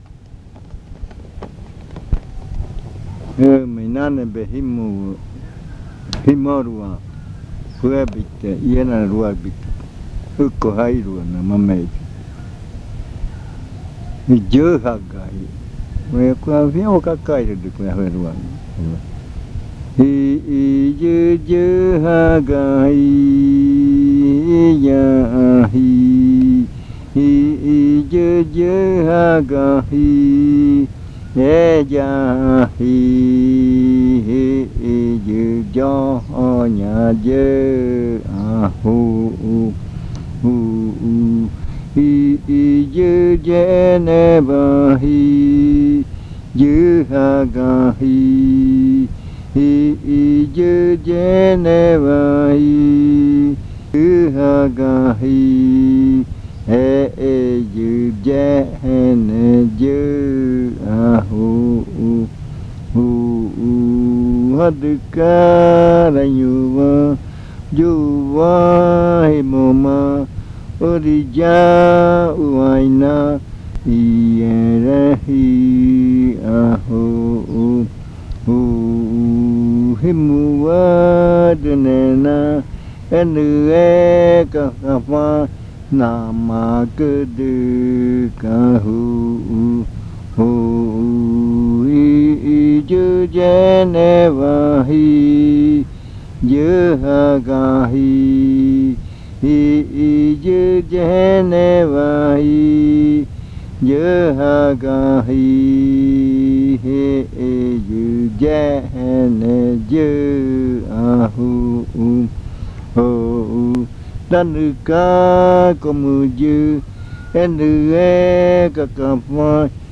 Leticia, Amazonas
Canción que se canta para animar el ritual. La canción manifiesta que invite a ir al baile a toda la comunidad
Chant that is sung to encourage the ritual.